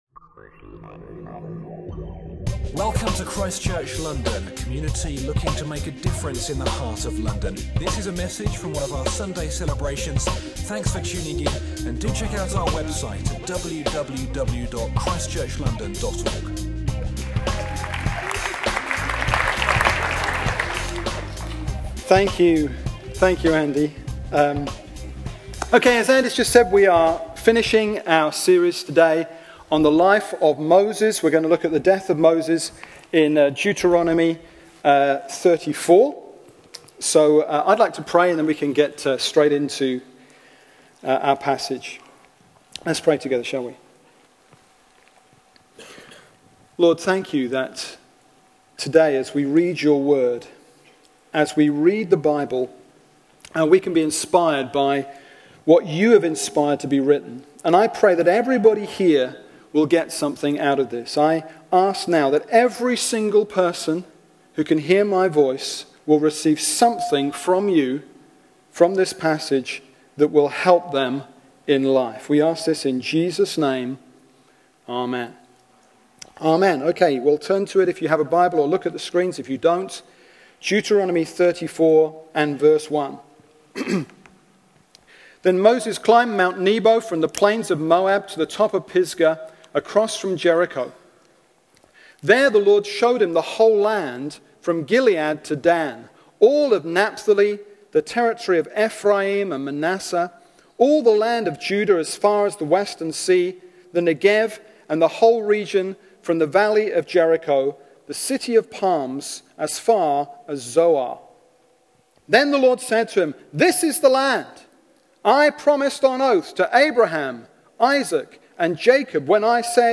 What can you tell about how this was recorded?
Dueteronomy 34:1-12 – Preaching from ChristChurch London’s Sunday Service